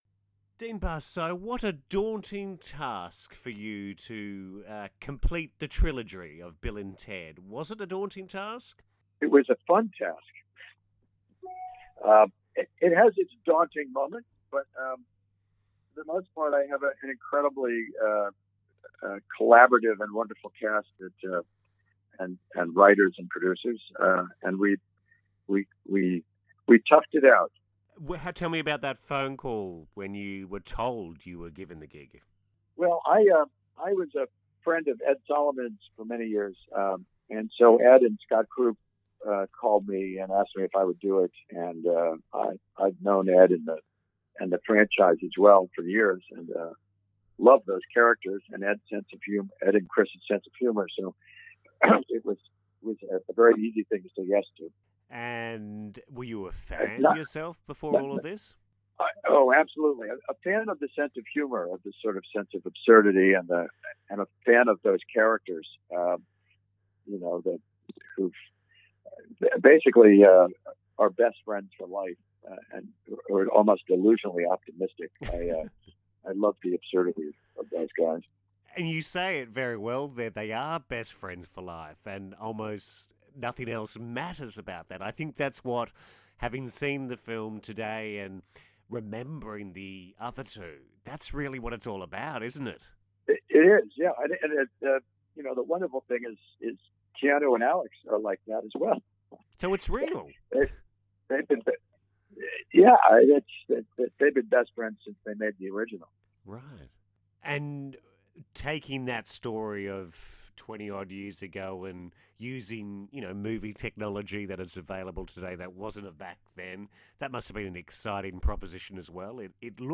CLICK BELOW to hear more from my chat with Director Dean Parisot
Bill-Ted-Director-Dean-Parisot.mp3